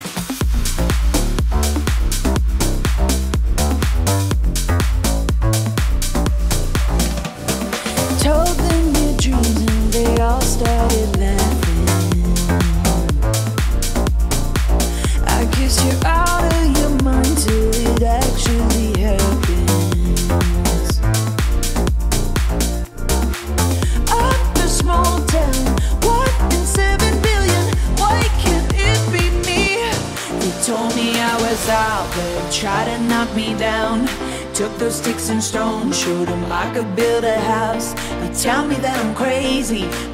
gratis en su teléfono en la categoría de Pop